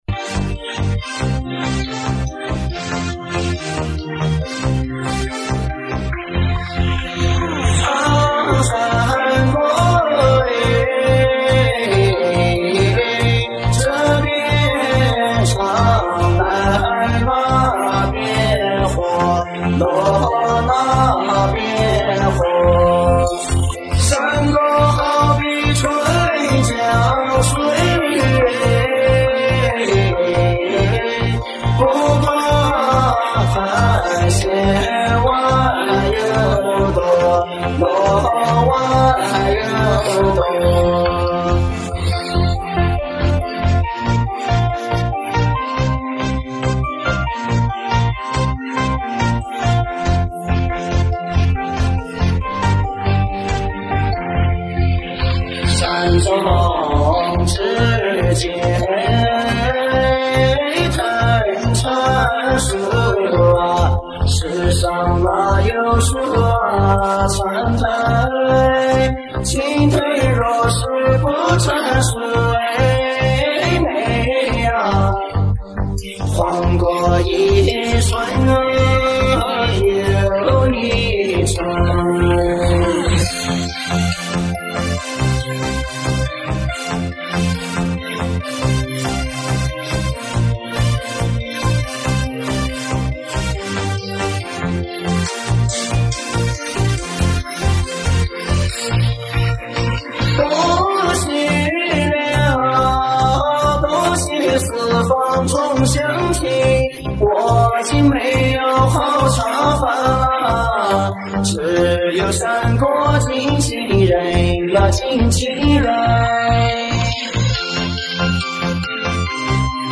DJ热歌